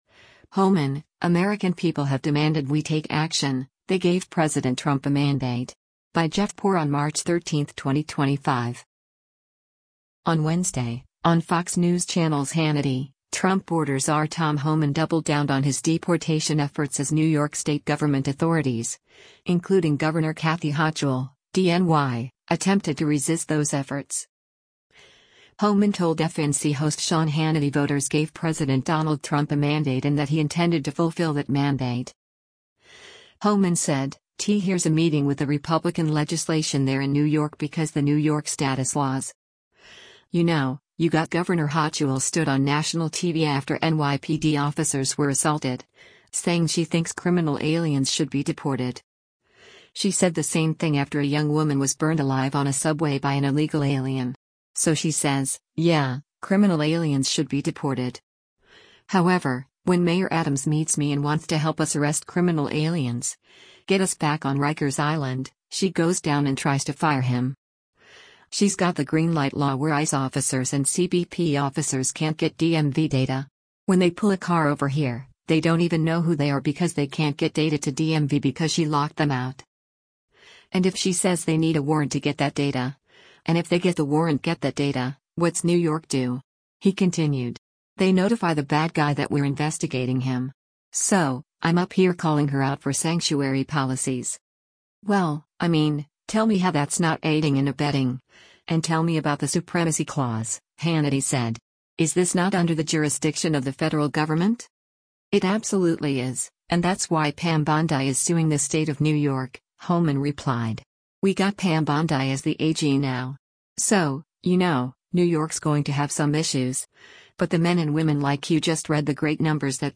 On Wednesday, on Fox News Channel’s “Hannity,” Trump border czar Tom Homan double-downed on his deportation efforts as New York State government authorities, including Gov. Kathy Hochul (D-NY), attempted to resist those efforts.
Homan told FNC host Sean Hannity voters gave President Donald Trump a mandate and that he intended to fulfill that mandate.